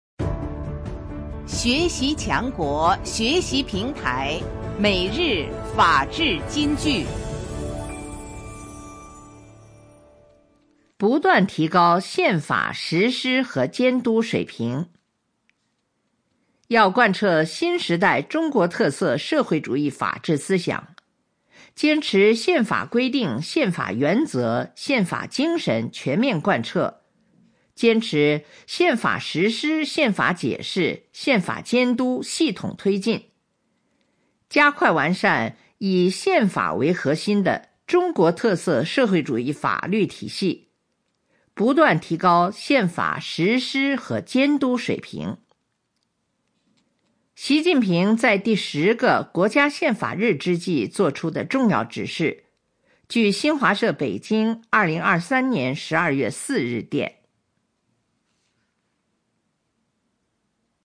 每日法治金句（朗读版）|不断提高宪法实施和监督水平 _ 学习宣传 _ 福建省民政厅